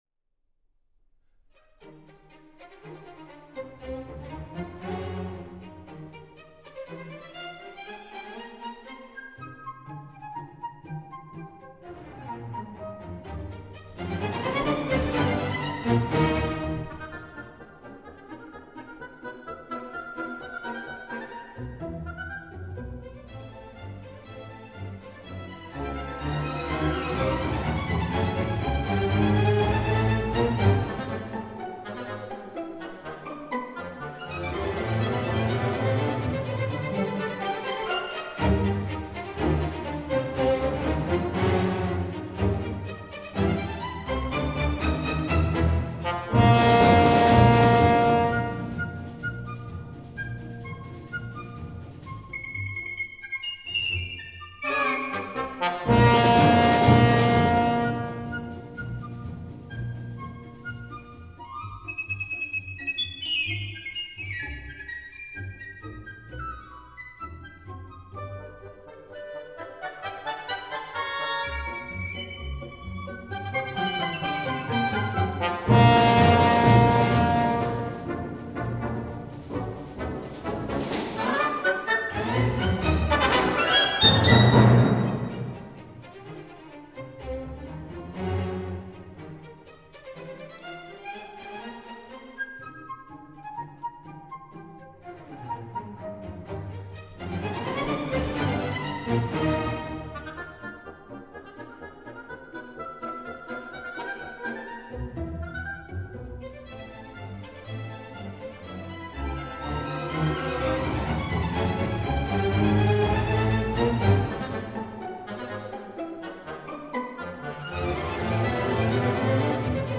et la moins grandiose et spectaculaire des trois.
courte et relativement légère symphonie en cinq mouvements